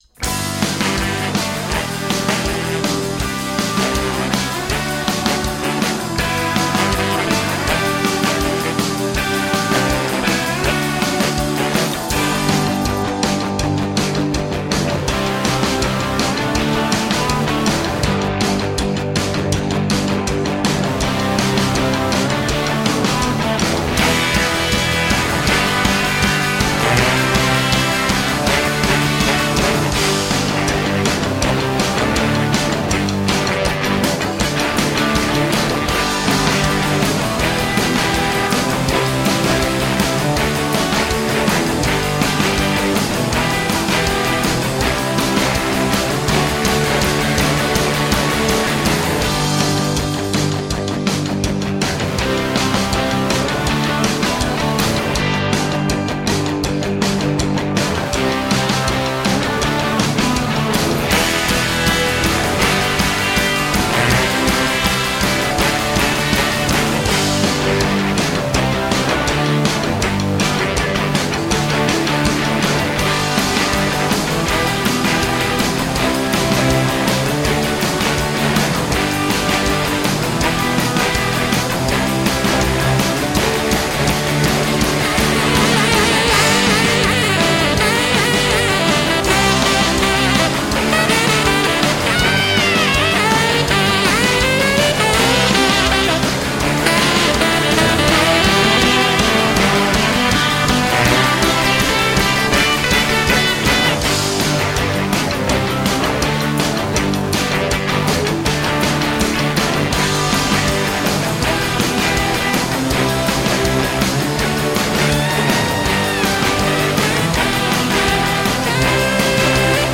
Nippy post-punk retro rock with a bite.
Tagged as: Hard Rock, Rock, Instrumental, Ironic Rock